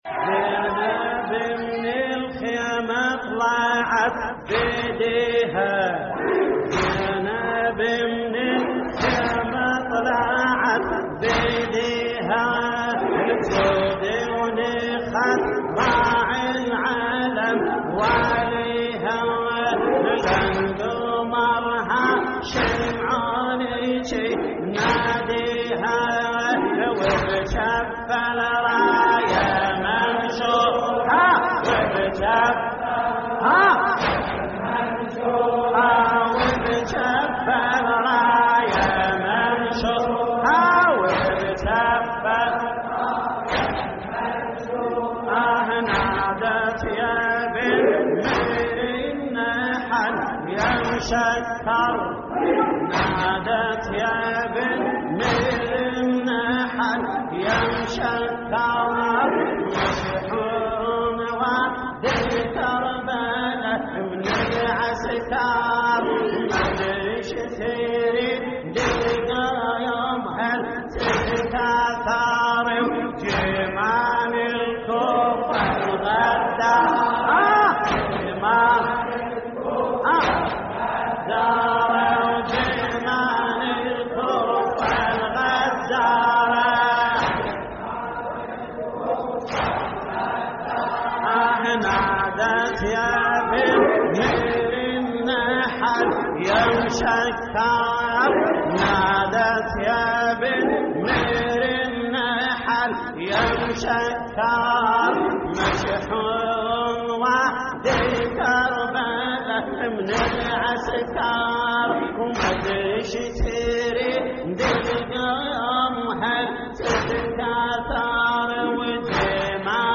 هوسات لحفظ الملف في مجلد خاص اضغط بالزر الأيمن هنا ثم اختر